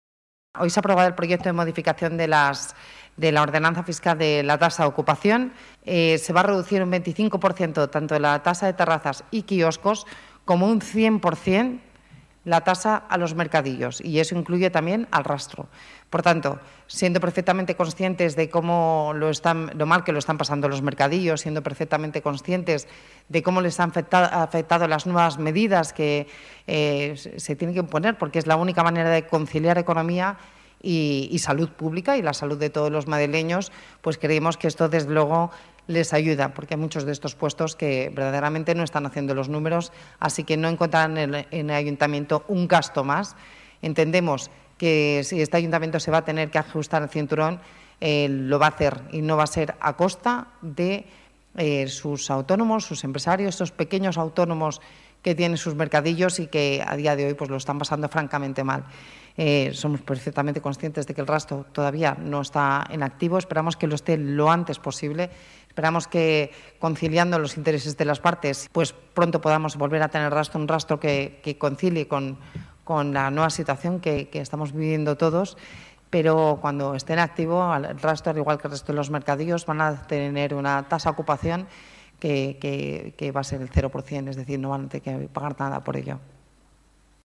Nueva ventana:Declaraciones de la vicealcaldesa en la Rueda de prensa tras la Junta de Gobierno